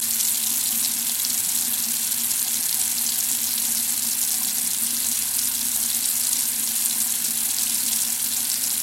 Звук струи воды, льющейся на высокой скорости